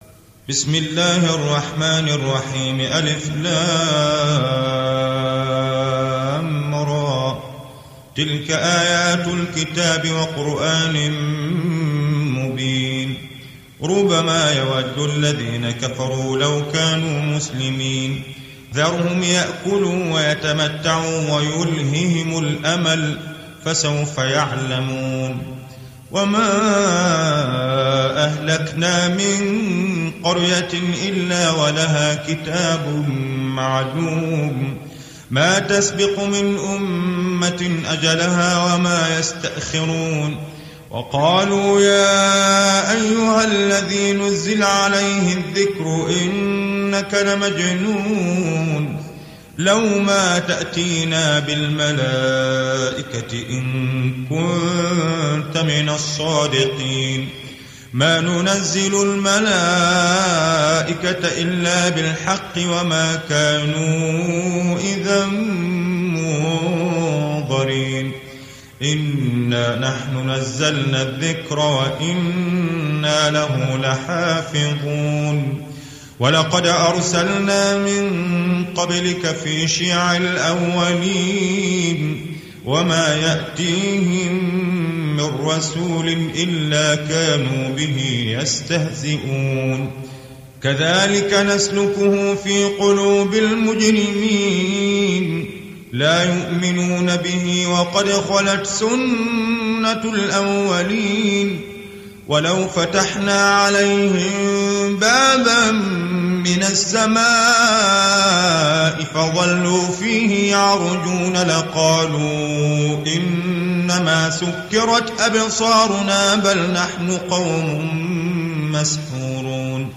تحميل سورة الحجر mp3 بصوت مصطفى اللاهوني برواية حفص عن عاصم, تحميل استماع القرآن الكريم على الجوال mp3 كاملا بروابط مباشرة وسريعة